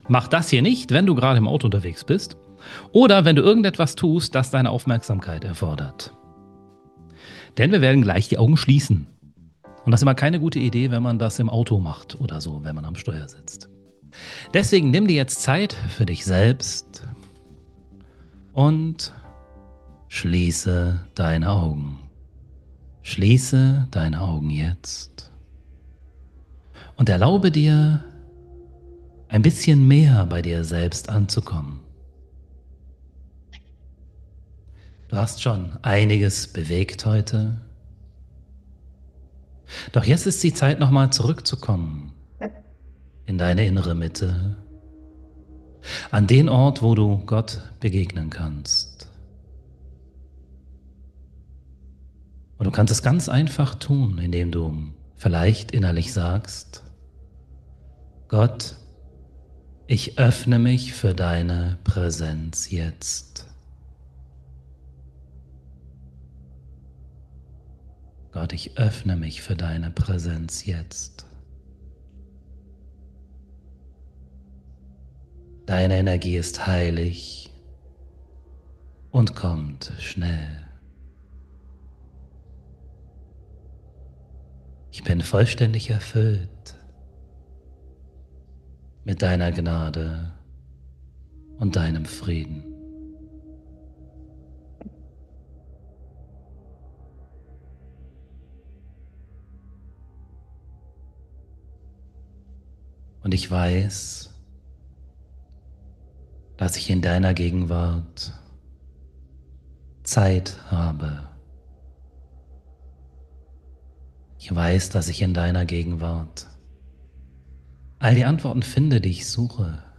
Praktische Meditation zum Auftanken "zwischendurch"